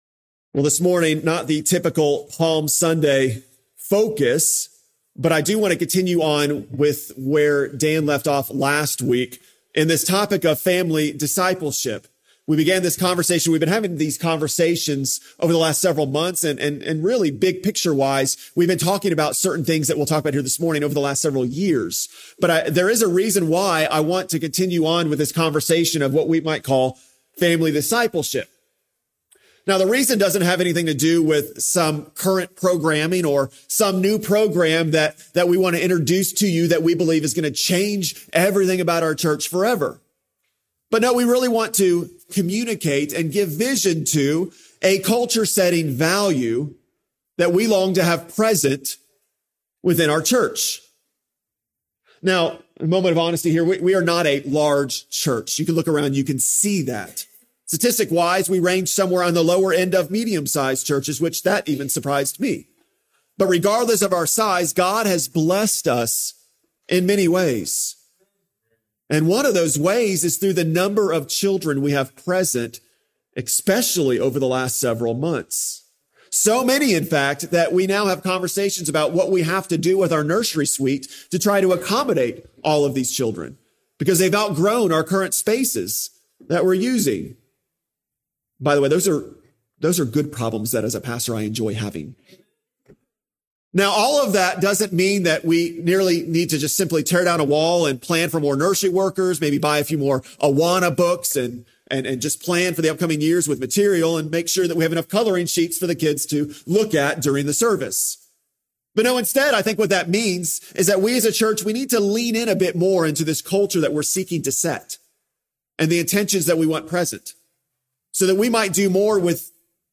3-29-26-Sermon.mp3